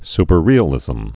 (spər-rēə-lĭzəm)